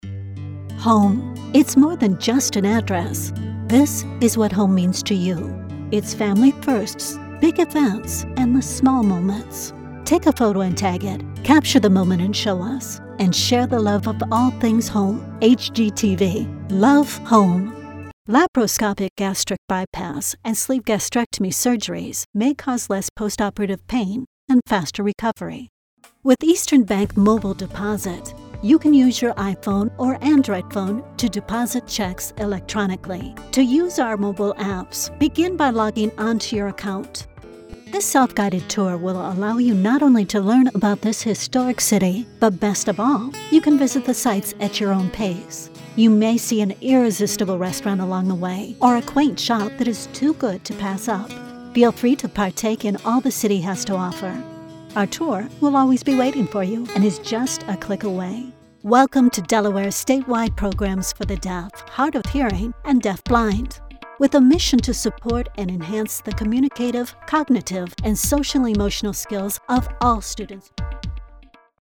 Amer. English, voice over, commercial, narration, documentary, Smooth Sophisticated Corporate Professional Warm Conversational unique sincere classy intelligent inviting appealing approachable,alto, relaxing, Soft spoken uplifting deep slightly raspy quality
middle west
Sprechprobe: Industrie (Muttersprache):